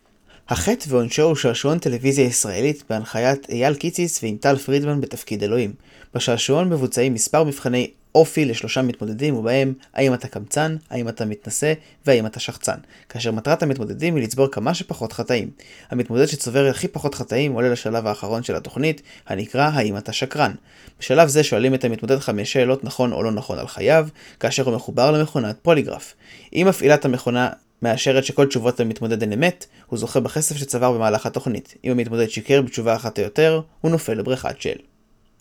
באשר לאיכות הסאונד, על מנת לבדוק באמת את יכולות המיקרופון, הכנתי הקלטה קטנה בה אני משווה את ה-HyperX SoloCast למיקרופון שנמצא על גבי אוזניות הגיימינג Corsair Void Pro RGB Wireless וכן ל-Razer Seiren Mini, הנכם מוזמנים להקשיב לכל אחת מין ההקלטות שכאן ולהחליט באופן אישי איזה מיקרופון מספק את הסאונד האיכותי ביותר:
באופן אישי, ה-SoloCast ללא ספק שבר את תקרת הציפיות שלי, ואם אשווה אותו באופן ישיר למתחרה הראוי בהחלט שלו, ה-Seiren Mini של Razer, הייתי אומר שאפילו שה-Seiren Mini מספק סאונד ברור יותר, עדיין הייתי מעדיף את ה-SoloCast בזכות התוספות הנלוות שלו של כפתור ההשתקה המובנה והסטנד המתקדם יותר.
Razer-Seiren-Mini.mp3